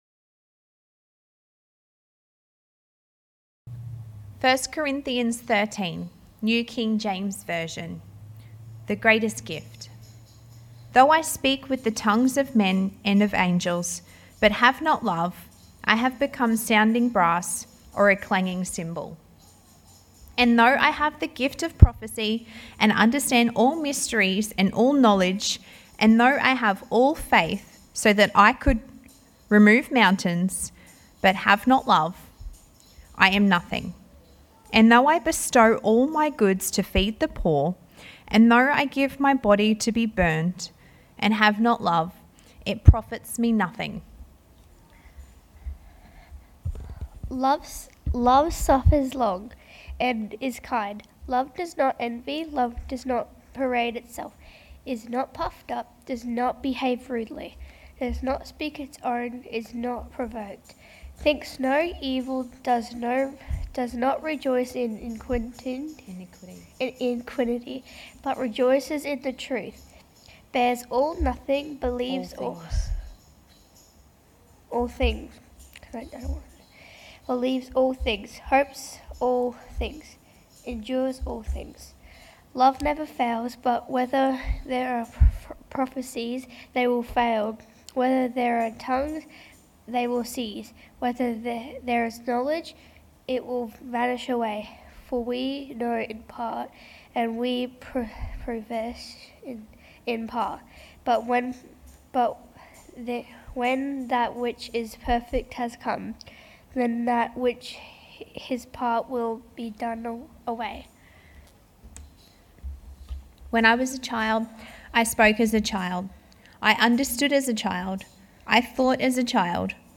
Note: Some items have been removed or maybe silent for a particular part of the service.